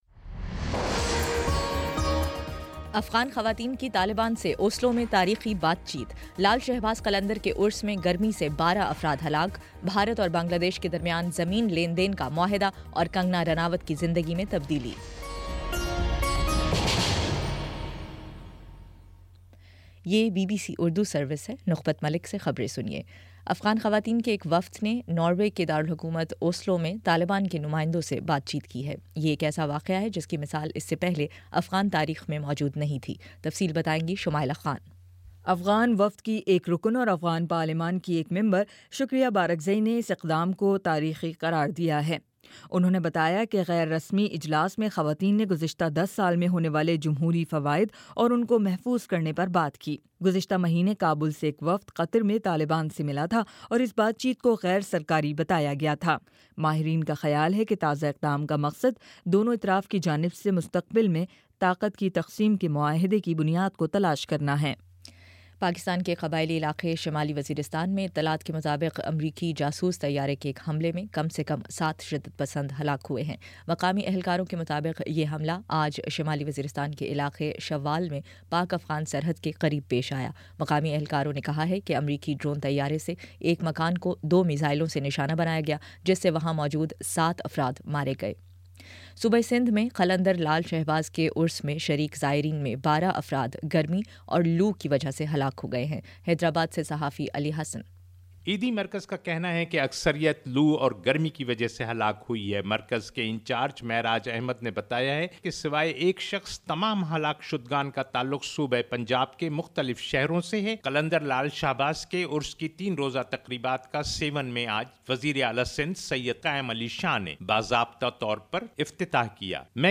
جون 6: شام پانچ بجے کا نیوز بُلیٹن